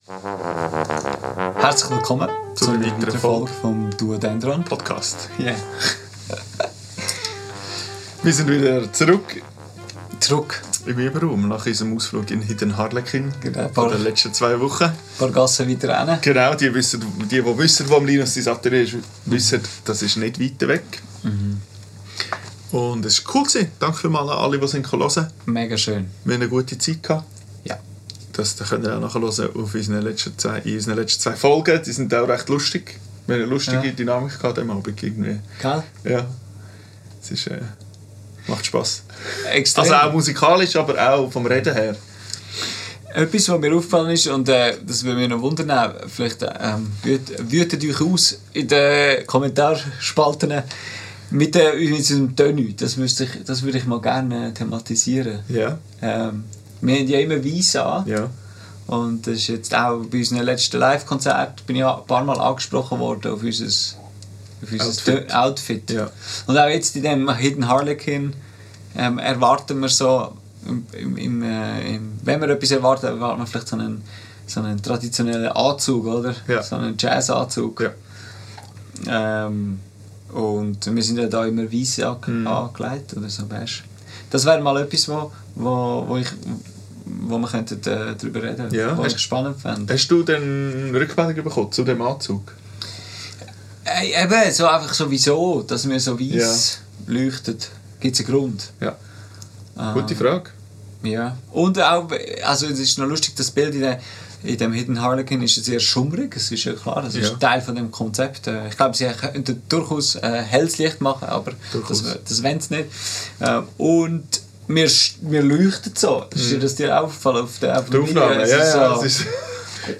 Warum tragen Musiker:innen was sie tragen? Ein spannendes Gespräch über Bühnen-Outfits.